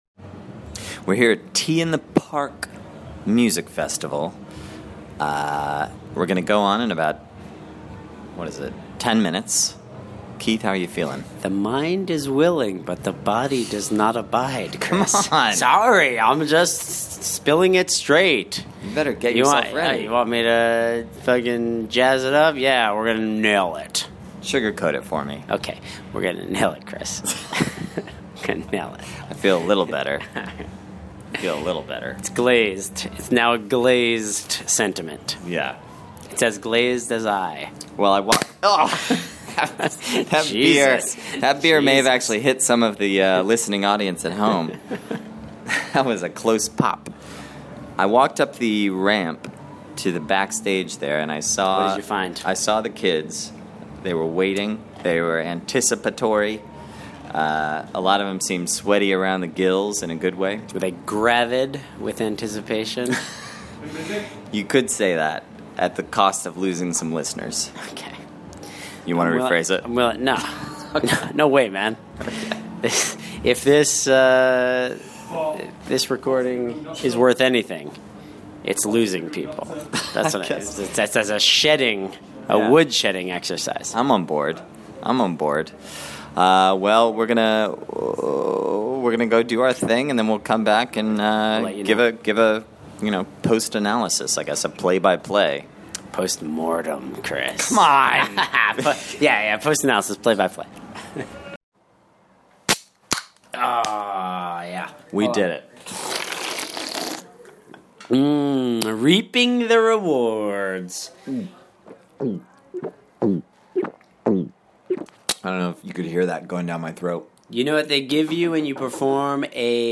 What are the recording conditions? At T-in-the-Park Festival in Scotland, we discuss the show before we go on, then break it down play by play afterward.